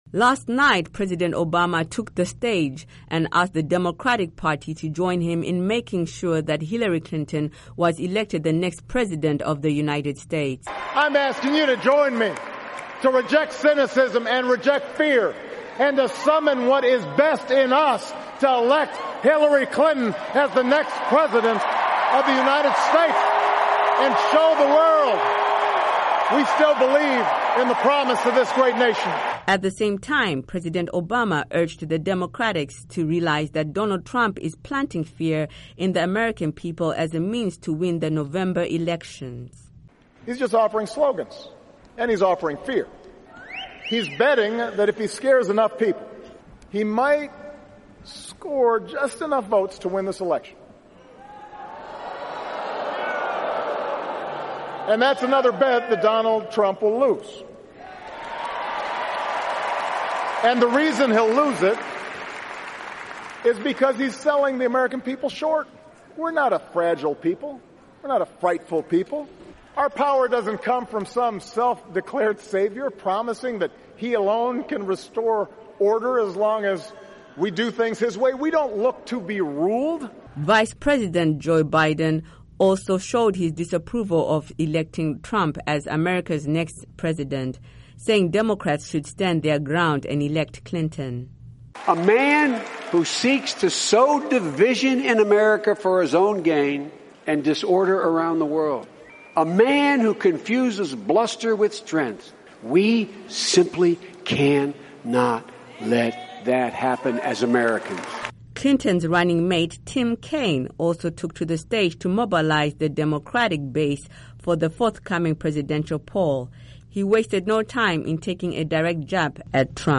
Report On DNC Convention